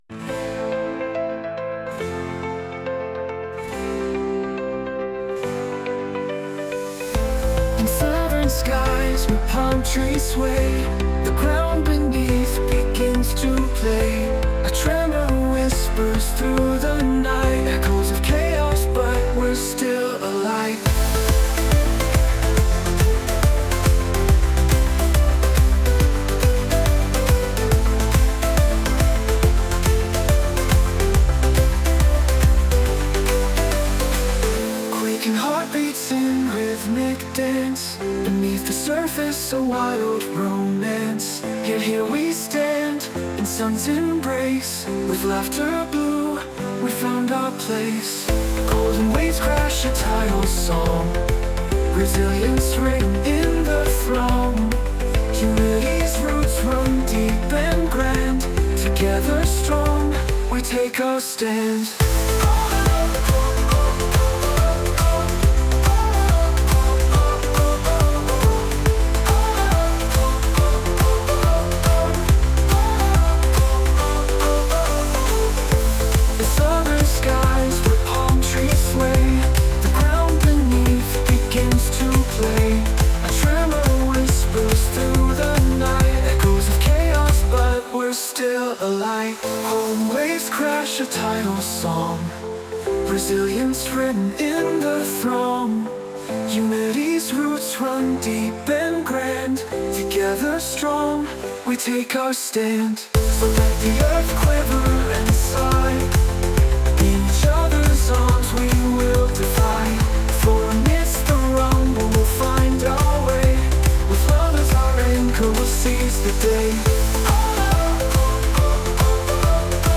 Songs (and quake image) generated by on-line AI
...then asked AI to put it to music...